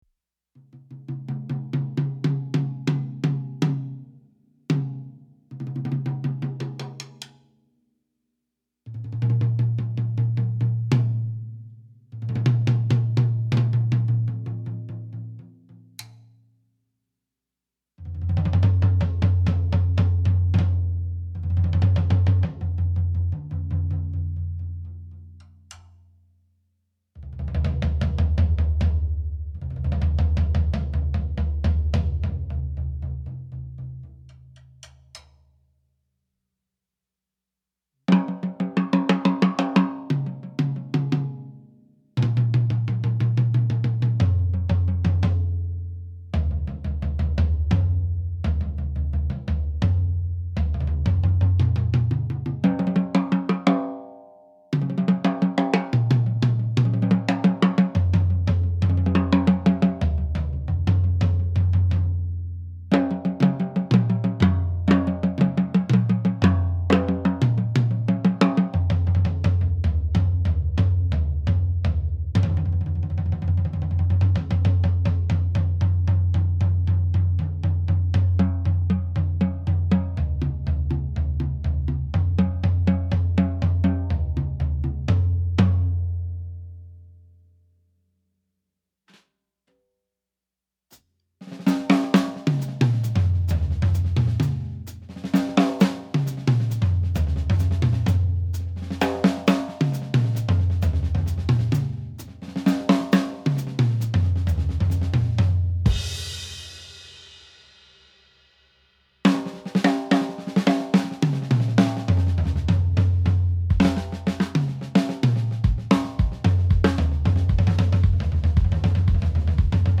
Ittelleni juttelen, mutta 13" ja 18" tomit löytyivät.
4tom.mp3